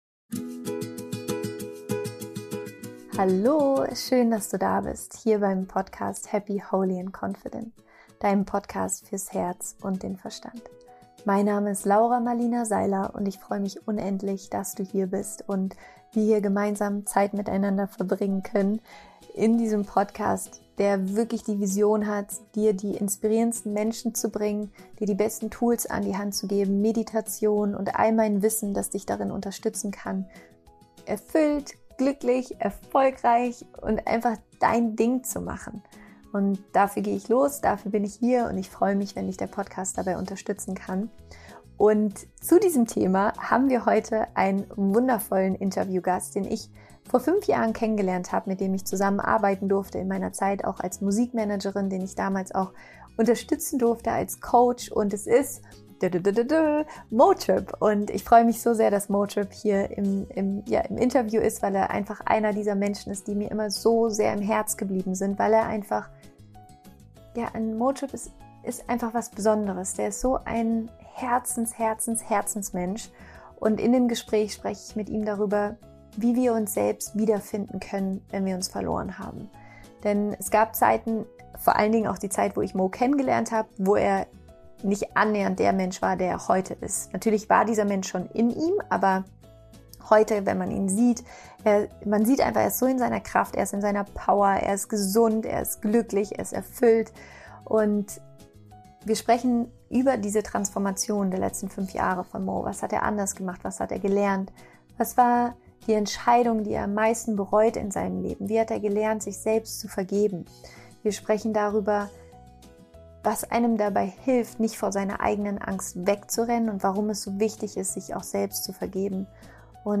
Es ist ein sehr tiefes, bewegendes Gespräch und ich wünsche dir viel Freude beim Anhören!